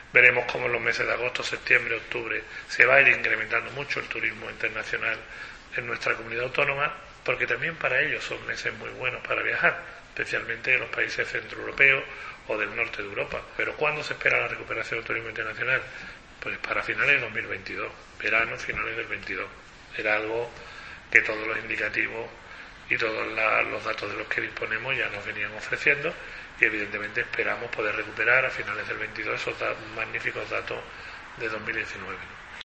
En una entrevista con Europa Press, Marín responde de esta forma cuando se le pone el ejemplo de alguna pintada callejera que aboga por "menos turismo, más industria".